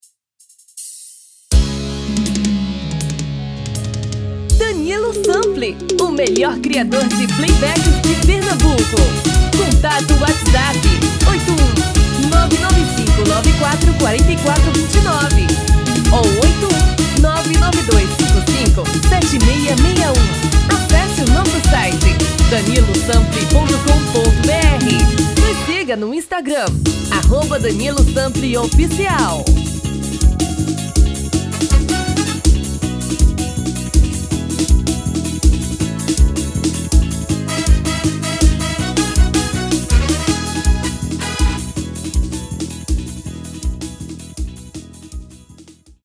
TOM FEMININO